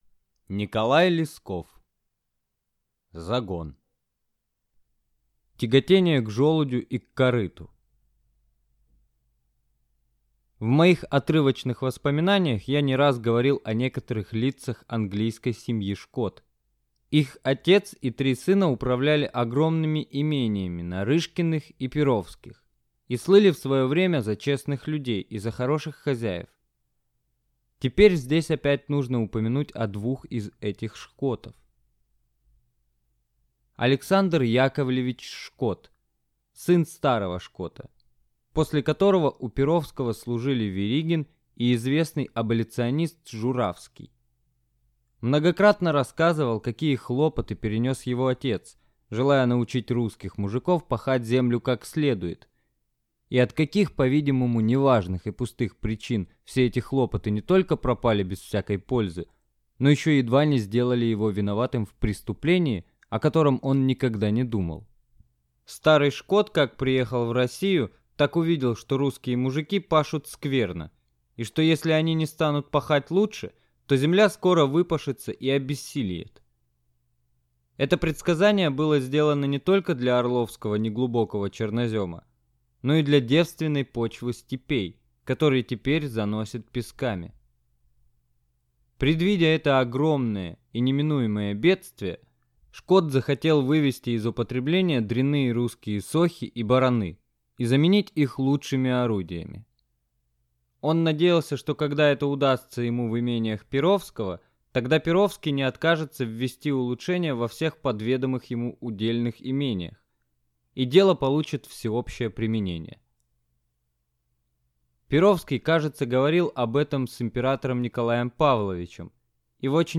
Аудиокнига Загон | Библиотека аудиокниг